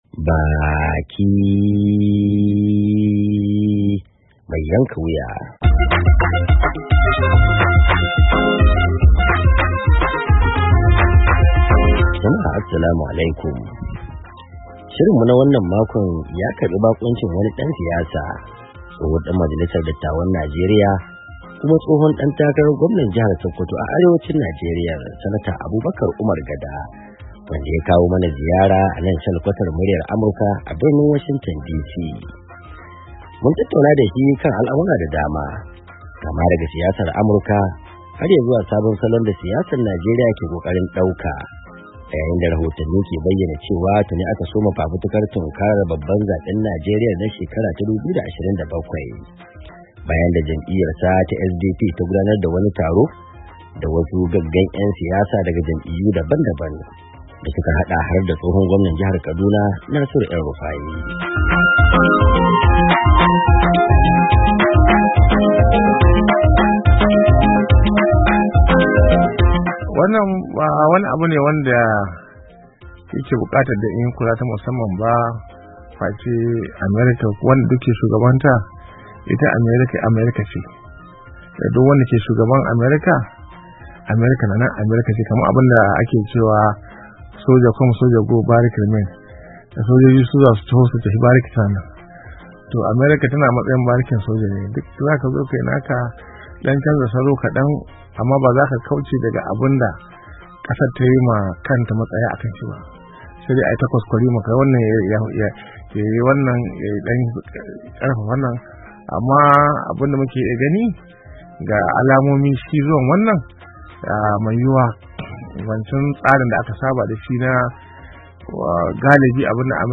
BAKI MAI YANKA WUYA - Tattaunawa Da Tsohon Dan Majalisar Dattawan Najeriya, Senata Abubakar Umar Gada